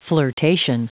Prononciation du mot flirtation en anglais (fichier audio)
Prononciation du mot : flirtation